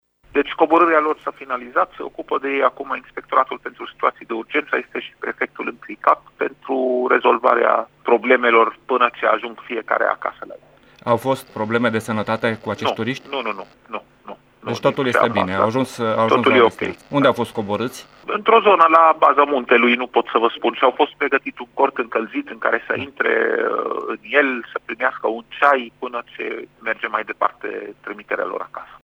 în dialog cu secretarul de stat în MAI, medicul Raed Arafat: